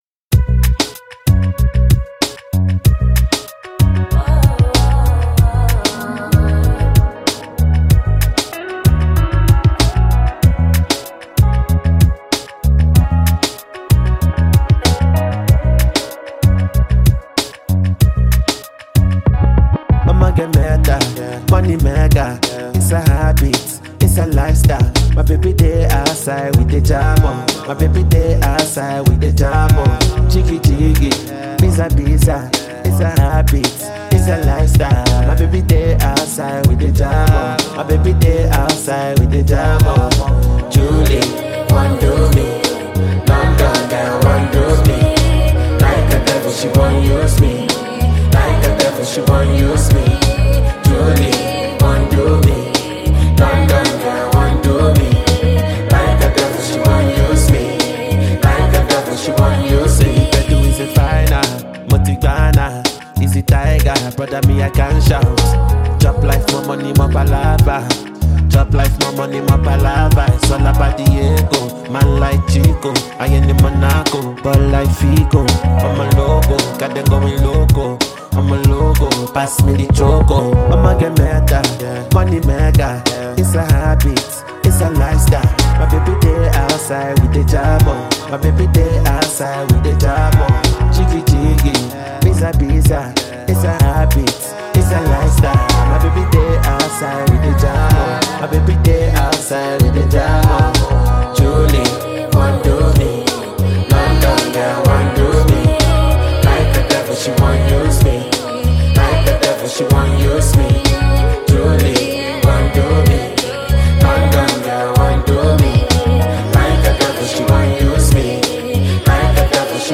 a song composer and afrobeat music superstar